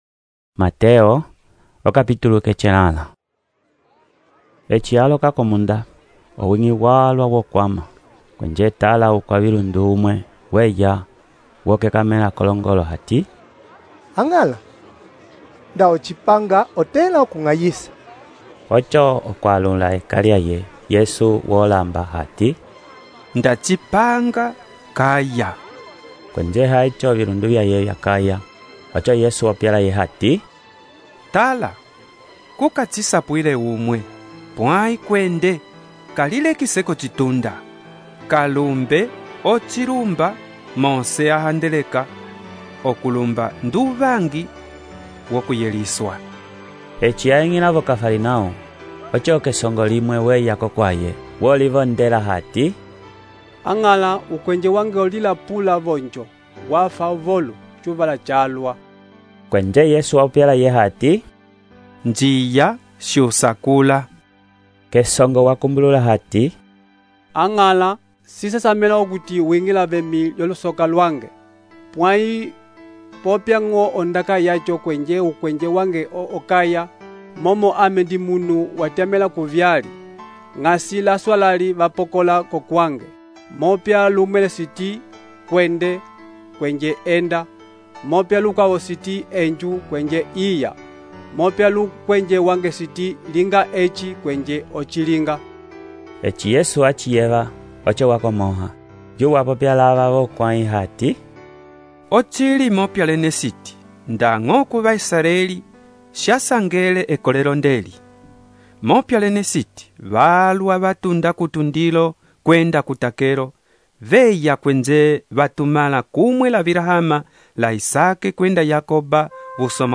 texto e narração , Mateus, capítulo 8